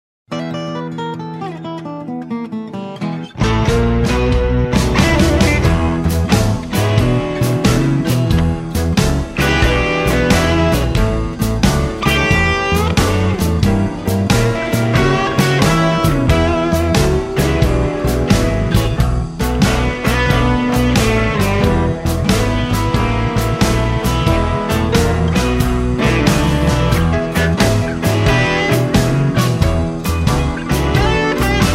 Instrumental Tracks.
▪ The full instrumental track